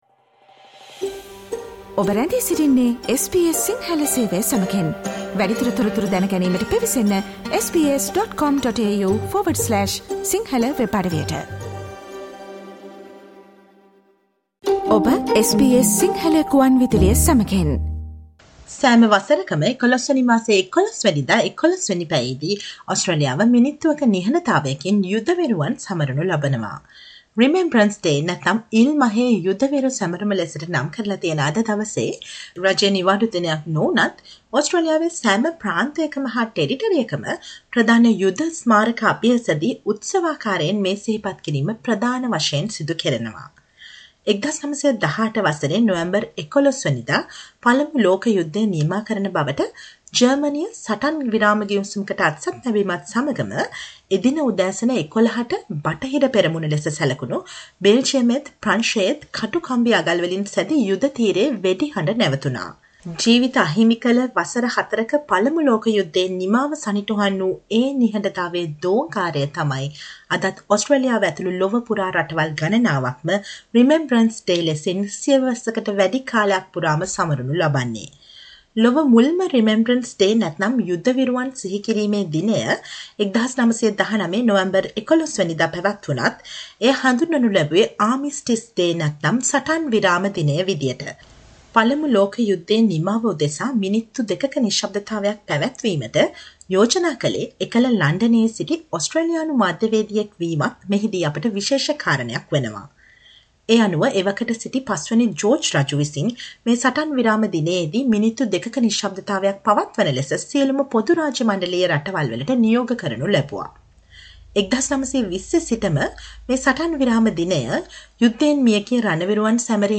Listen to the SBS sinhala radio special feature revealing views of Sri Lankan Australians on remembrance day.